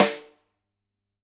Index of /musicradar/Snares/Ludwig B
CYCdh_LudRimB-04.wav